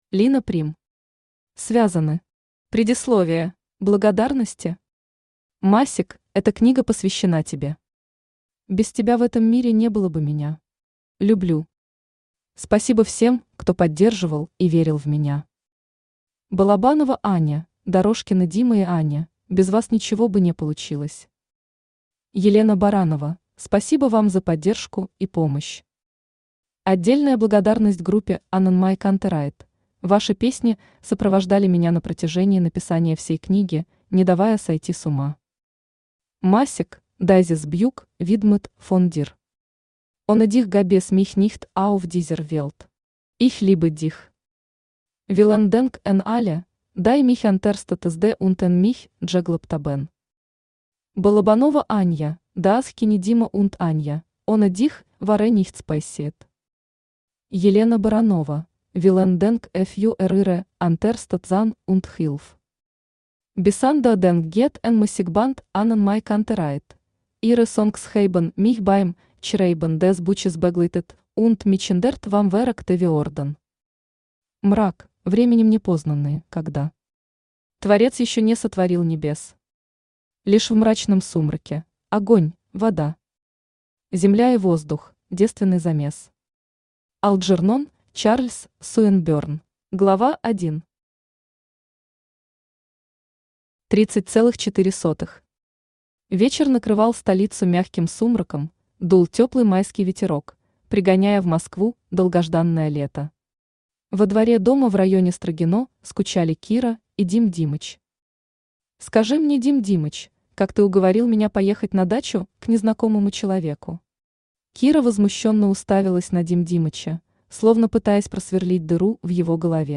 Предисловие Автор Лина Прим Читает аудиокнигу Авточтец ЛитРес.